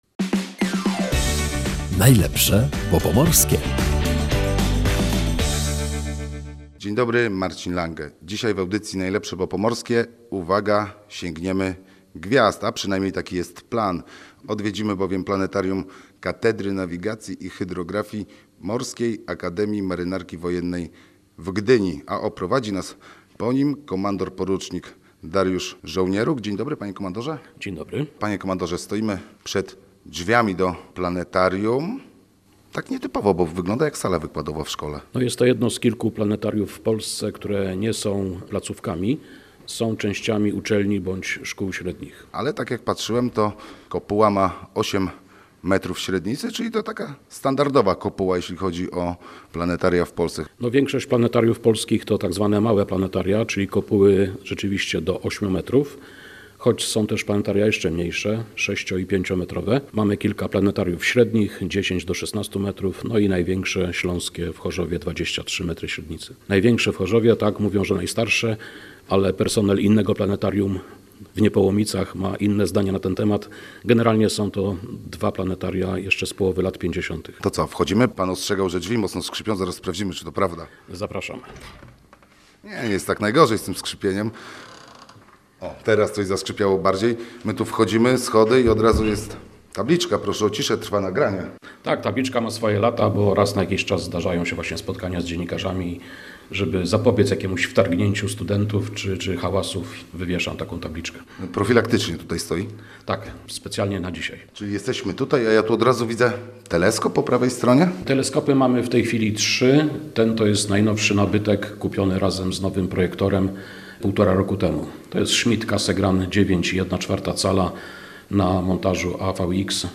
W audycji "Najlepsze Bo Pomorskie" odwiedzamy jedno z dwóch gdyńskich planetariów - planetarium Katedry Nawigacji i Hydrografii Morskiej Akademii Marynarki